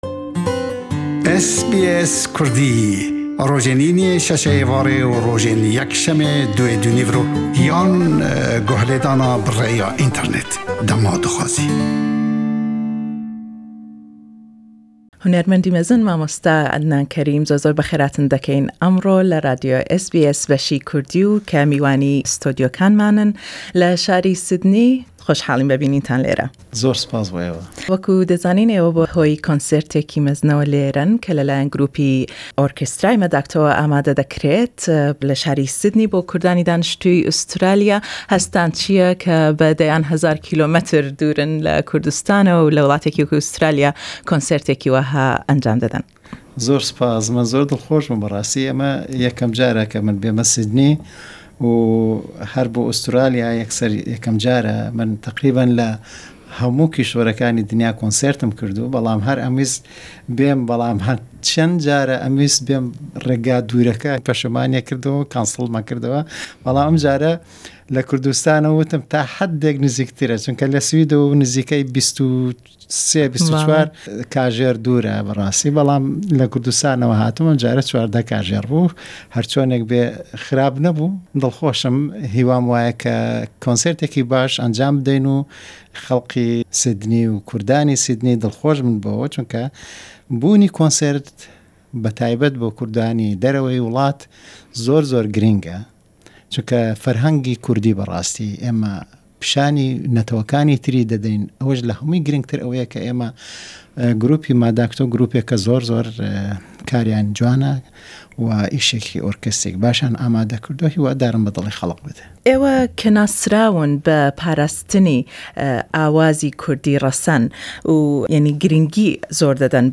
Adnan Karim in SBS studios Source: SBS Kurdish